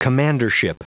Prononciation du mot commandership en anglais (fichier audio)
Prononciation du mot : commandership